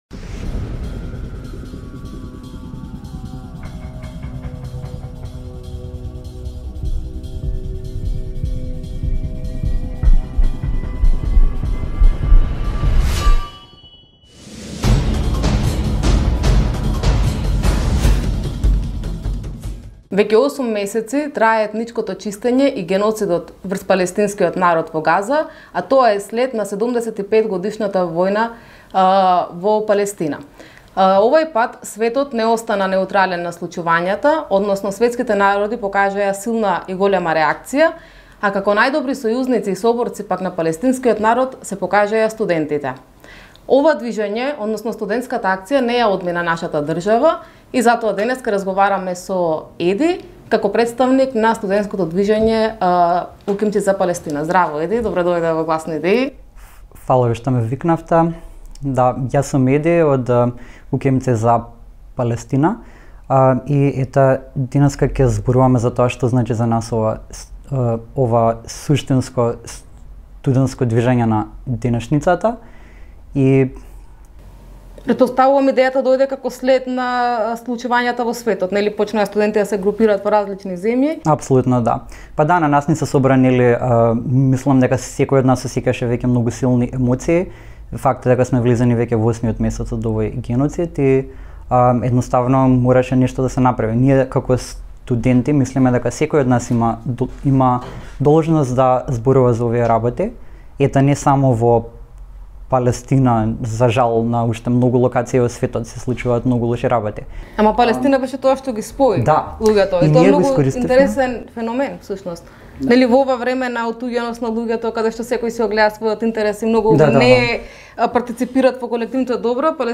ИНТЕРВЈУ: Зошто УКИМци за Палестина?